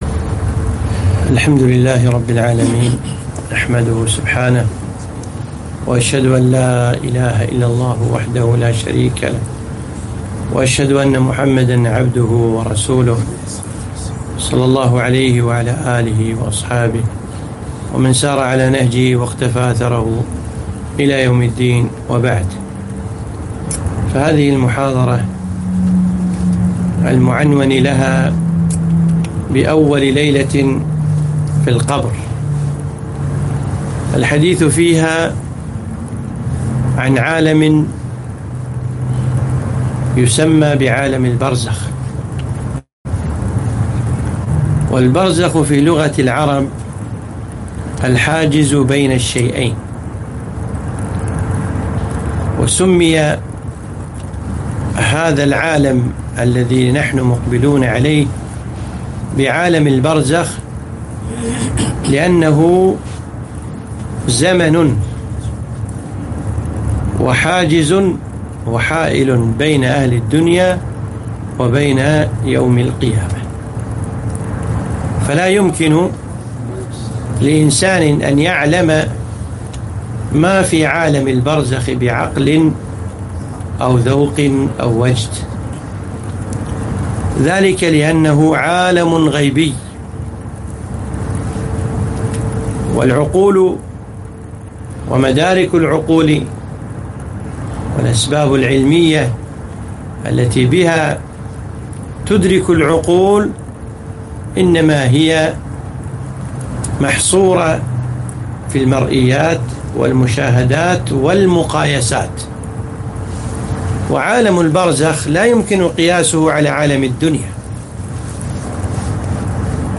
محاضرة - أول ليلة في القبر